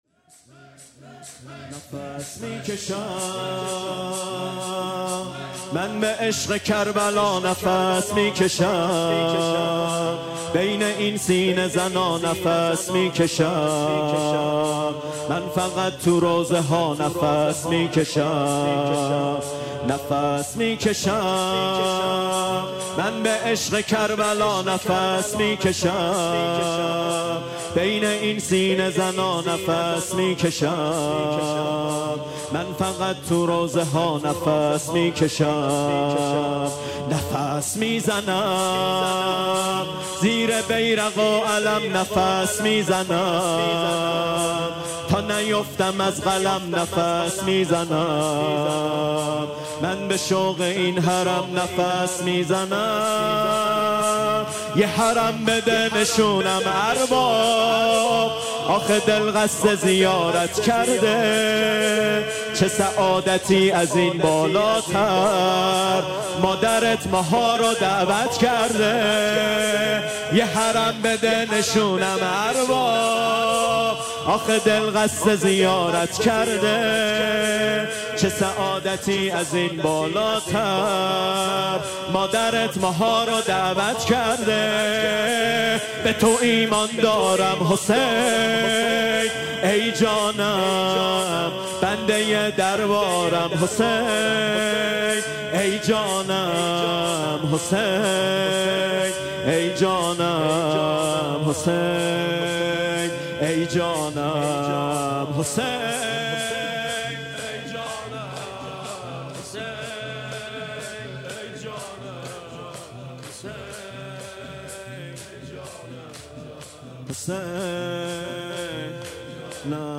شب هفدهم ماه رمضان/ ۲۹ فروردین ۴۰۱ شور مداحی ماه رمضان اشتراک برای ارسال نظر وارد شوید و یا ثبت نام کنید .